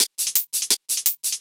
Index of /musicradar/ultimate-hihat-samples/170bpm
UHH_ElectroHatB_170-02.wav